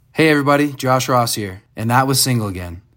LINER Josh Ross (Single Again) 4